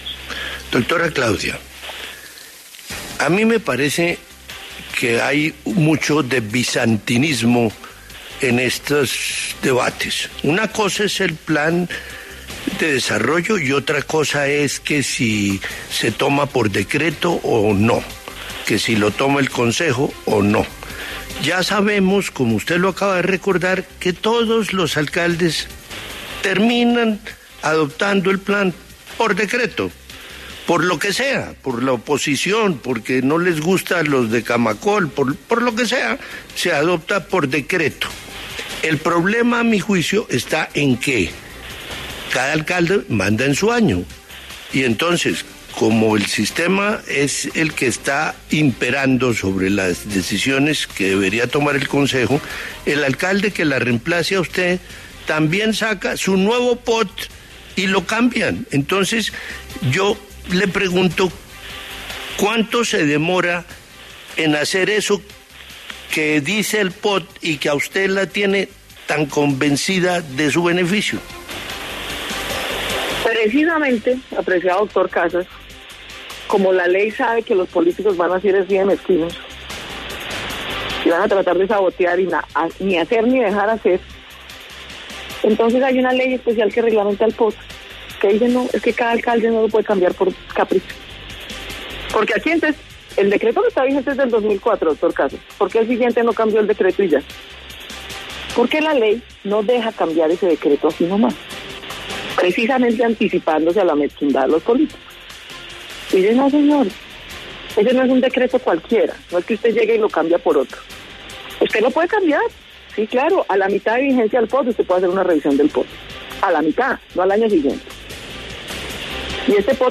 La alcaldesa de Bogotá, Claudia López, respondió en La W las críticas que ha recibido por la aprobación del POT por decreto y aseguró que está usando “su facultad” para solucionar temas como el desempleo y la inseguridad.